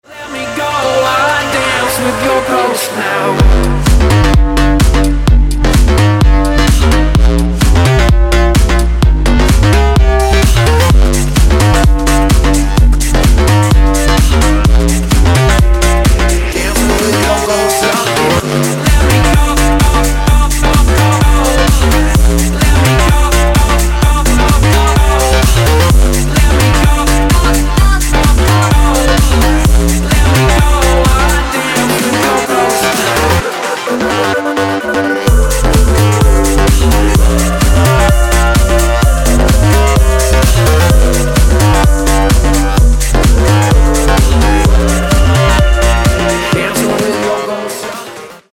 club
house
electro